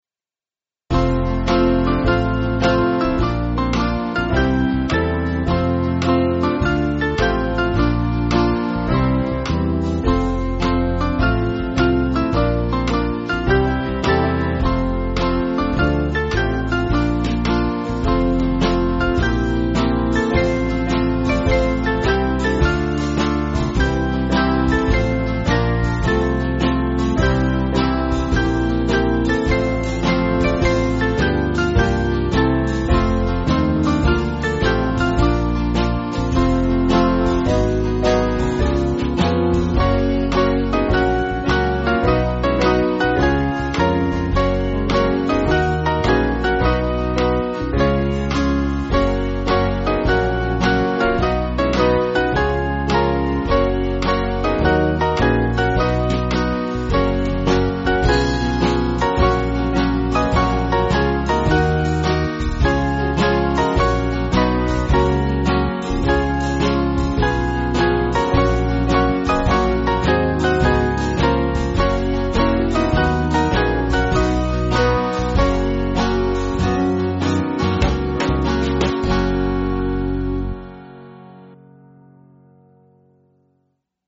Swing Band
(CM)   2/F-Gb